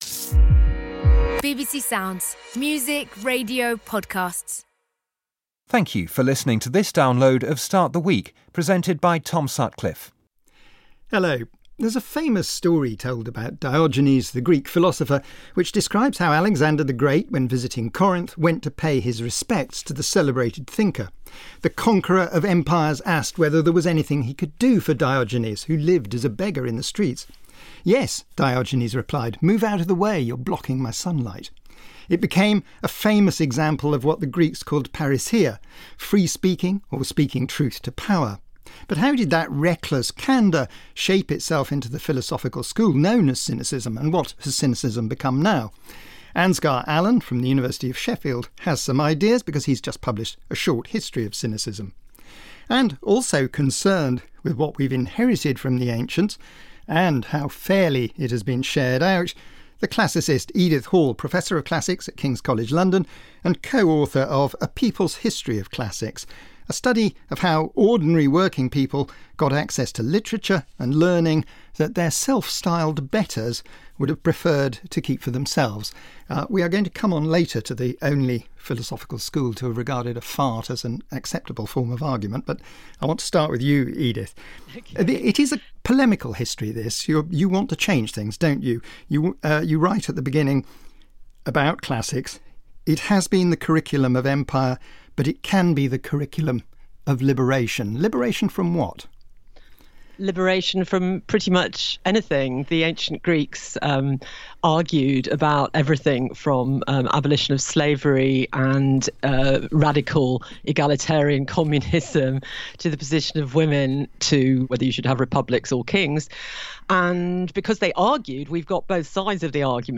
BBC RADIO 4 START THE WEEK INTERVIEW https